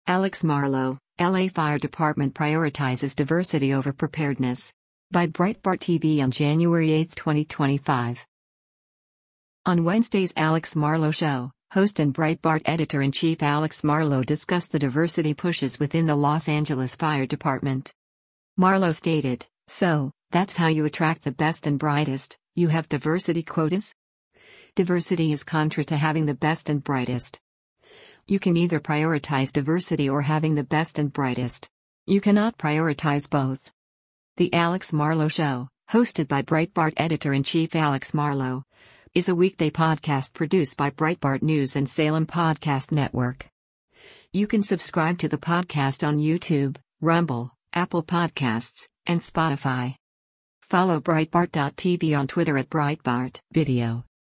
On Wednesday’s “Alex Marlow Show,” host and Breitbart Editor-in-Chief Alex Marlow discussed the diversity pushes within the Los Angeles Fire Department.